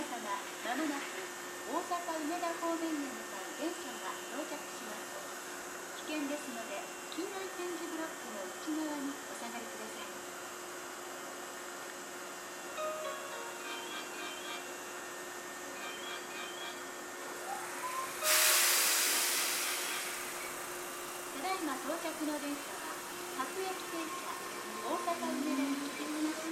この駅では接近放送が設置されています。
２号線HK：阪急神戸線
接近放送普通　大阪梅田行き接近放送です。